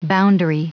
Prononciation du mot boundary en anglais (fichier audio)
Prononciation du mot : boundary